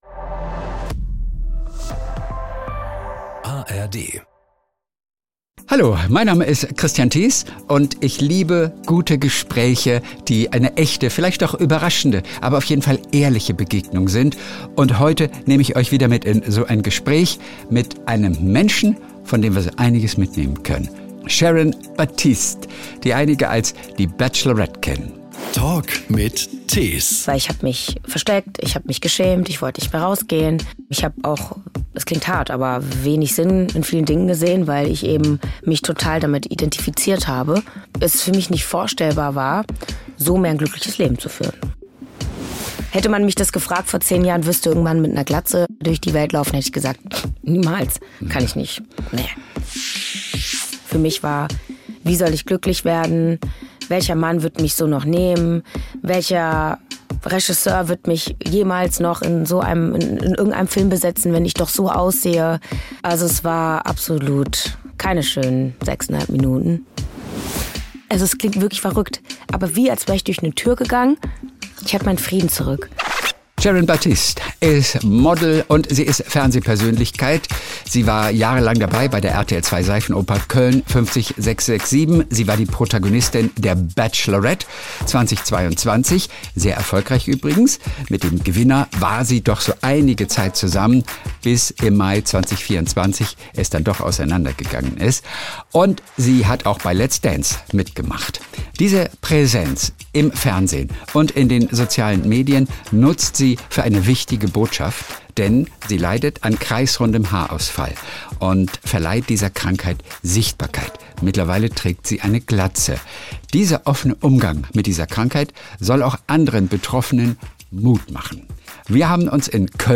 Eine warmherzige, offene und humorvolle Begegnung.